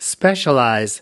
/əˈkwaɪər/